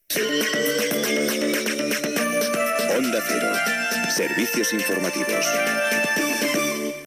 Idicatiu del programa
Informatiu